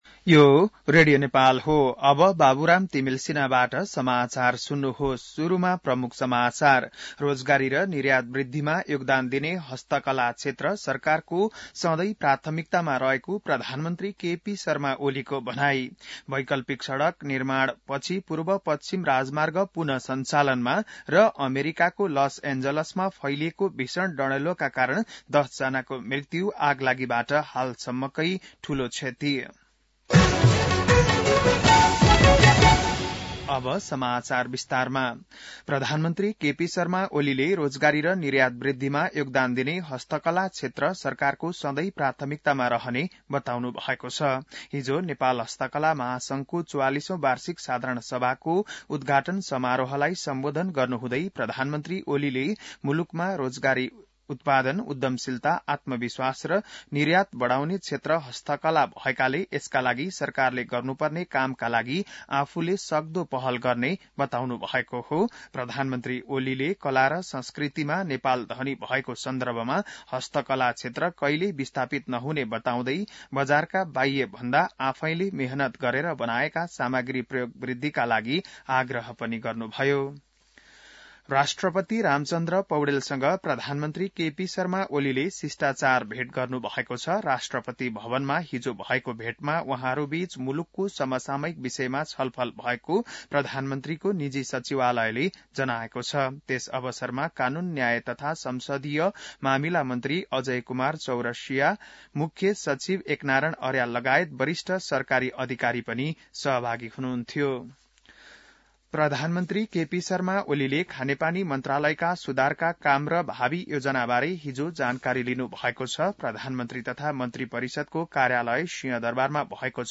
बिहान ९ बजेको नेपाली समाचार : २८ पुष , २०८१